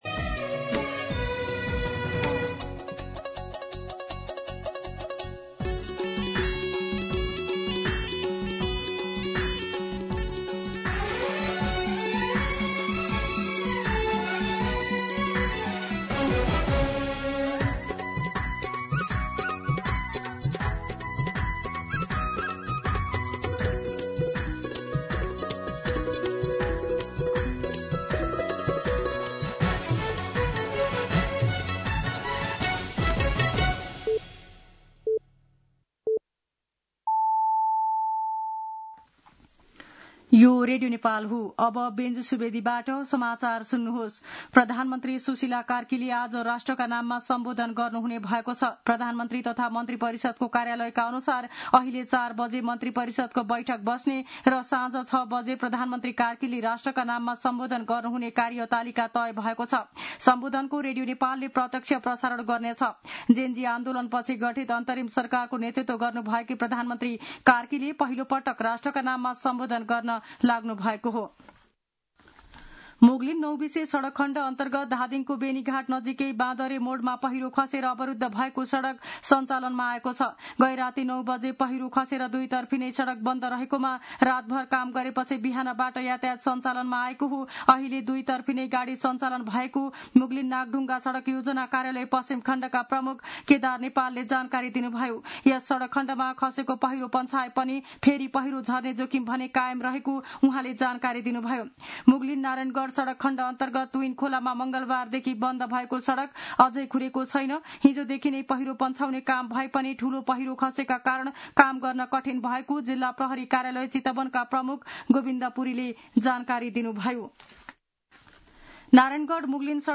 दिउँसो ४ बजेको नेपाली समाचार : ९ असोज , २०८२